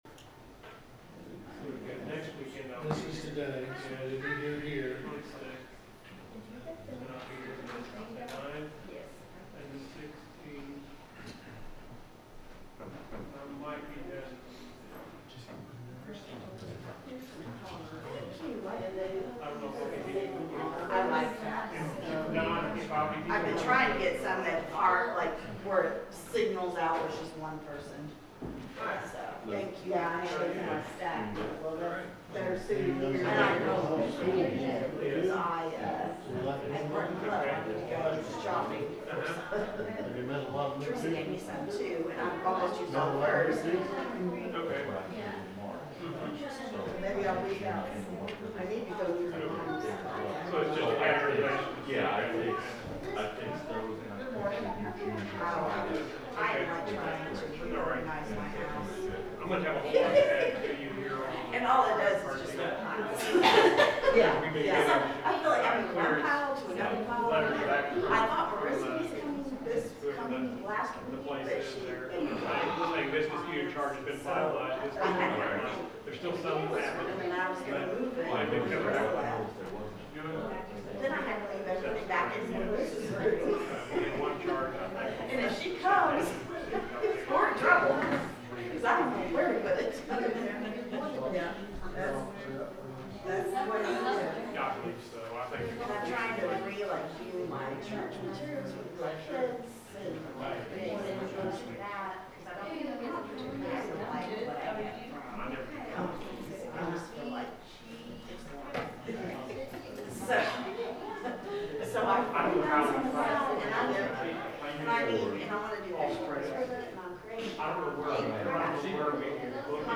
The sermon is from our live stream on 10/19/2025